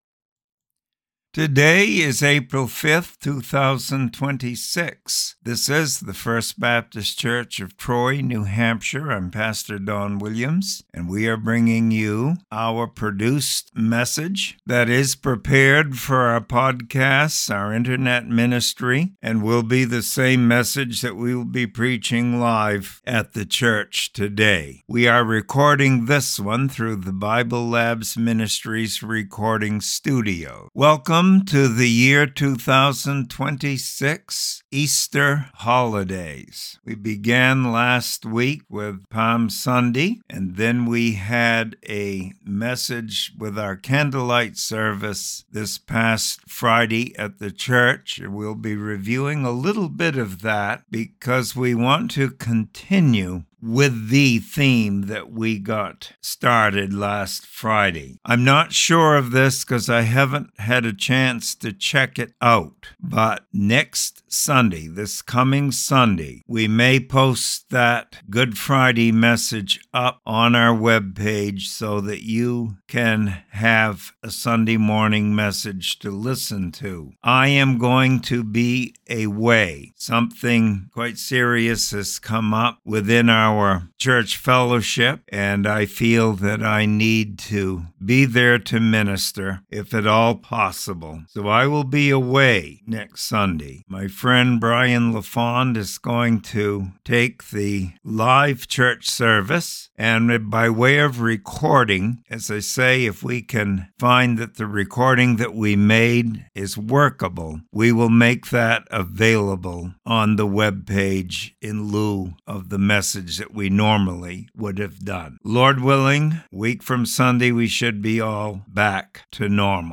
Easter Message - Where did Jesus go after the Resurrection?